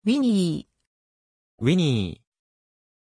Pronunciation of Winnie
pronunciation-winnie-ja.mp3